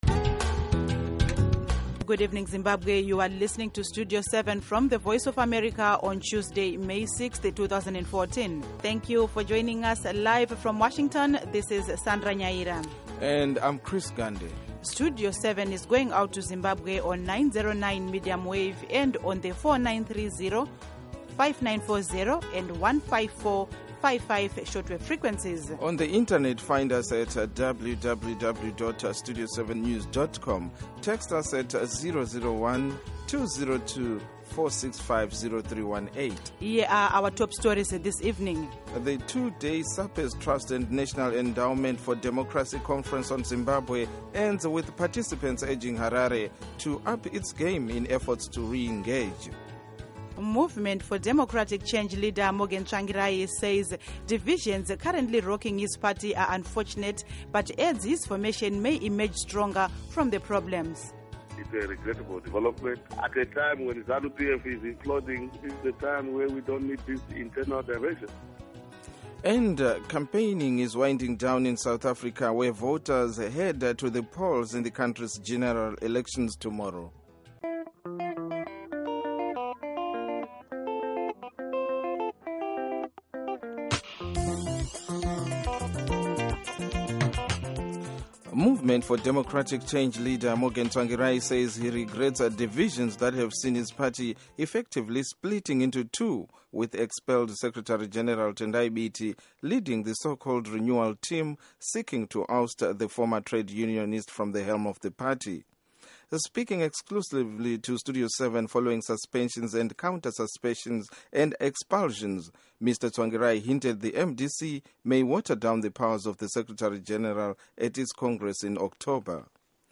In part two of the interview Morgan Tsvangirai says top MDC-T leaders should not push him out of office.